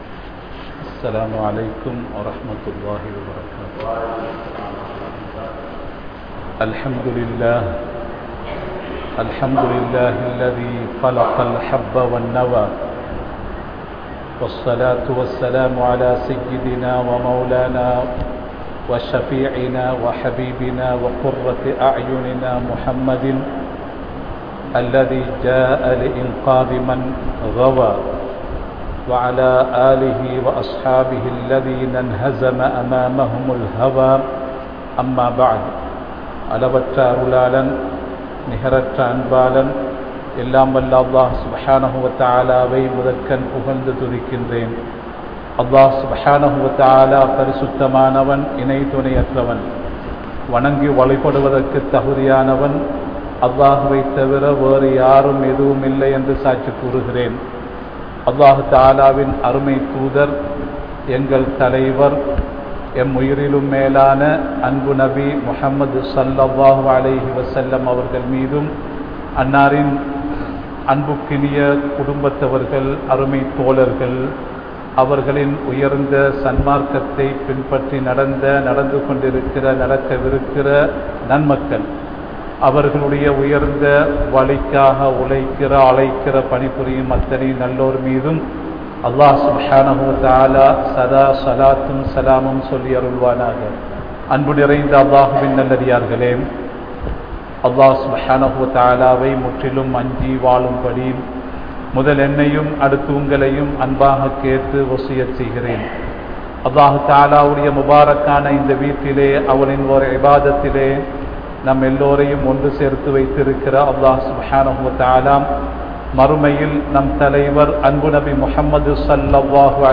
Khutbas-2023